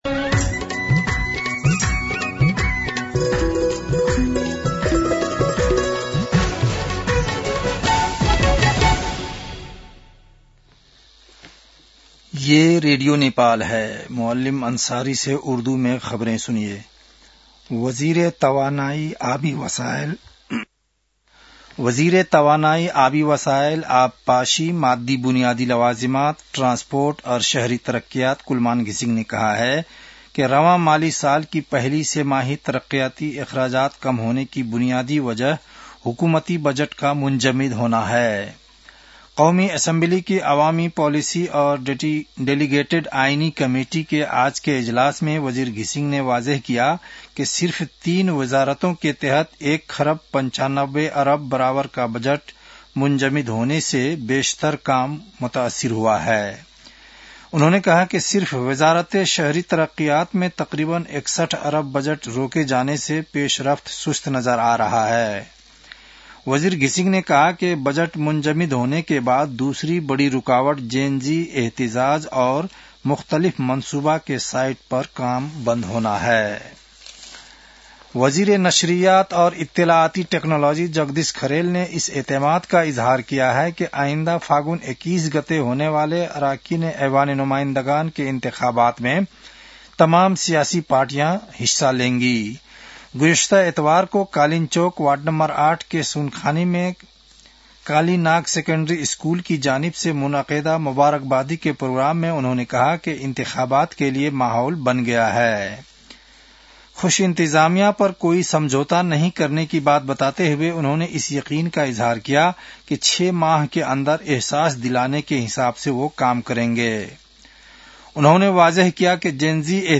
उर्दु भाषामा समाचार : ८ मंसिर , २०८२